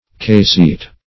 Search Result for " keyseat" : The Collaborative International Dictionary of English v.0.48: Keyseat \Key"seat`\, v. t. To form a key seat, as by cutting.